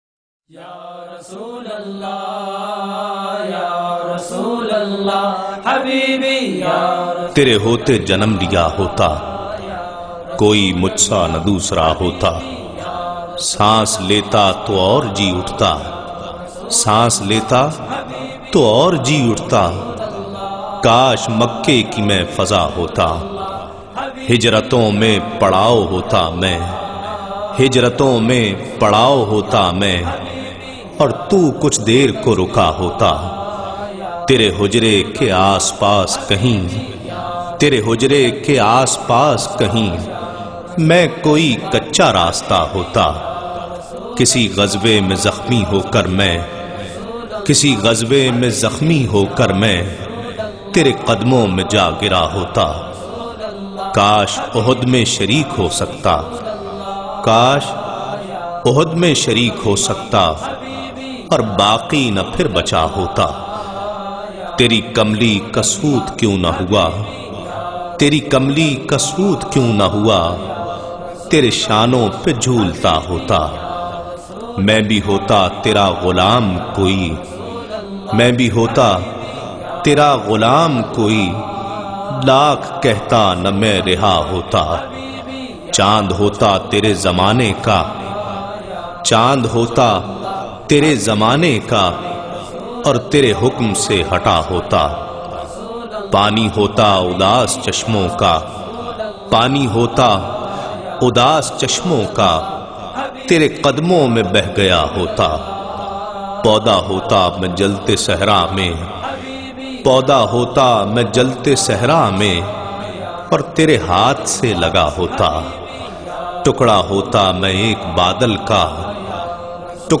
URDU NAAT
in a Heart-Touching Voice